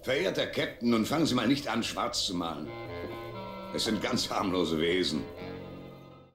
Arnold Marquis beruhigt als Cyrano Jones Kirk.
(TAS: Mehr Trouble mit Tribbles / Invasion der Wollmöpse, ZDF-Synchronfassung)